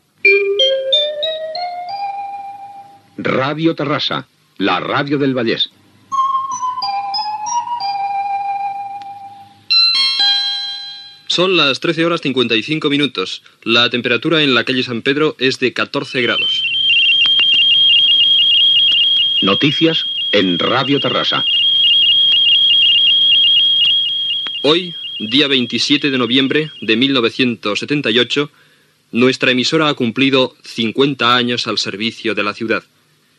Indicatiu de l'emissora, hora, temperatura, identificació del programa, notícia de la celebració dels 50 anys de l'emissora
Informatiu